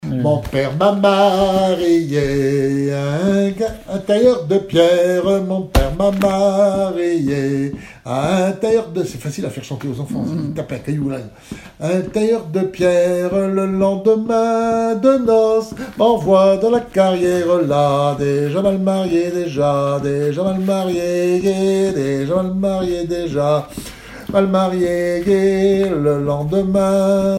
Mémoires et Patrimoines vivants - RaddO est une base de données d'archives iconographiques et sonores.
Genre laisse
Enquête Douarnenez en chansons
Pièce musicale inédite